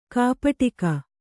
♪ kāpaṭika